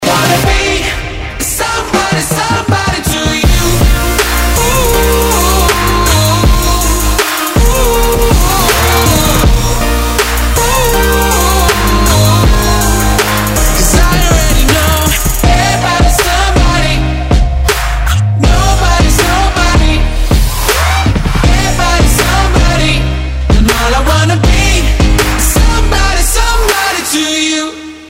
Клубные рингтоны